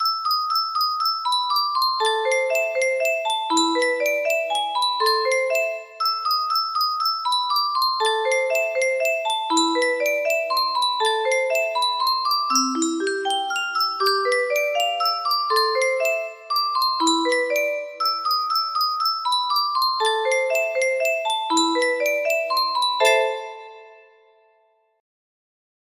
엘리제를 위하여 music box melody
Grand Illusions 30 (F scale)